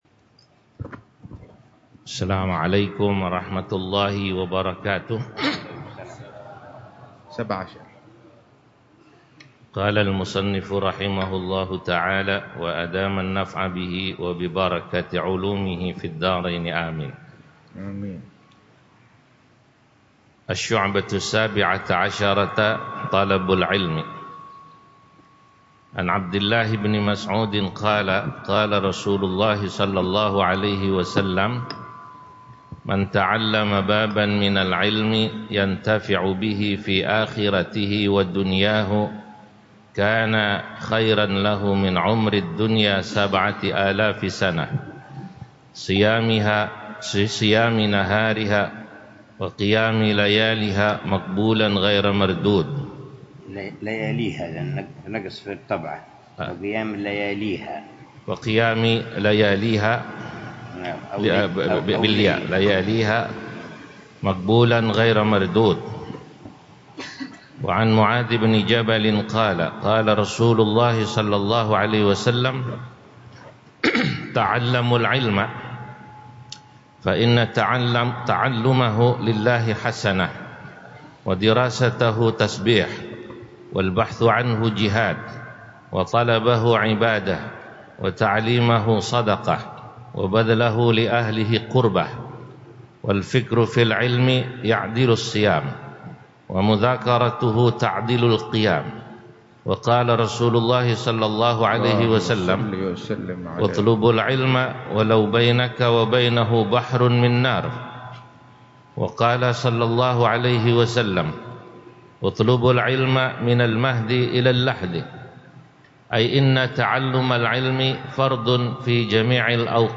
الدرس السادس للعلامة الحبيب عمر بن حفيظ في شرح كتاب: قامع الطغيان على منظومة شعب الإيمان، للعلامة محمد نووي بن عمر البنتني الجاوي، في مسجد الا